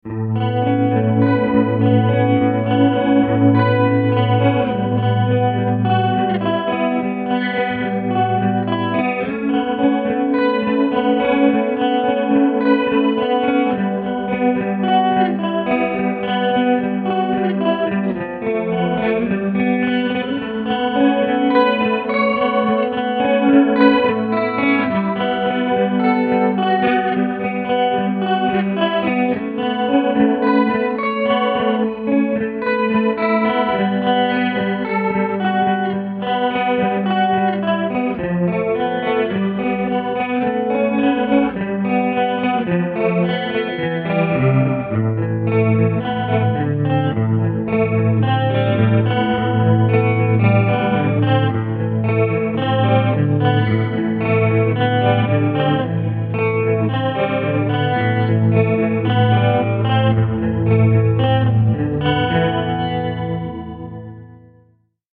It's an electric guitar, likely my Ibanez, but I don't know anything else.